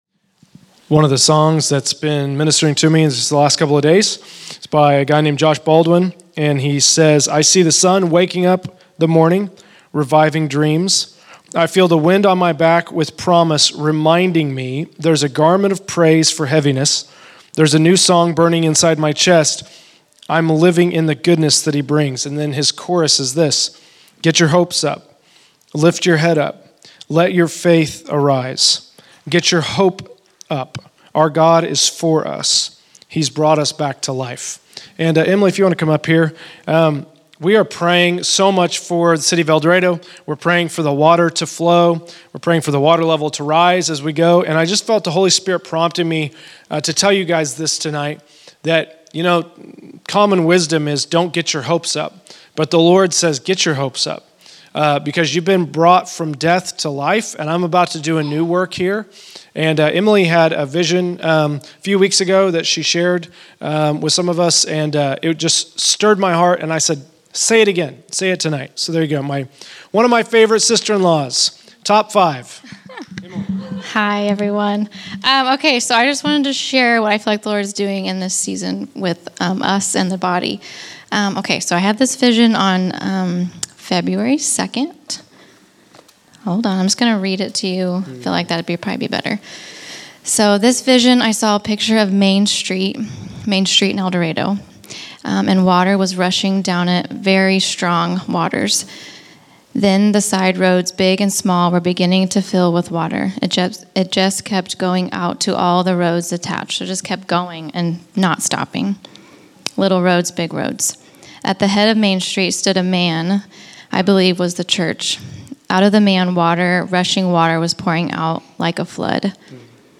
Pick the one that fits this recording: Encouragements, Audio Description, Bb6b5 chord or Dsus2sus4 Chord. Encouragements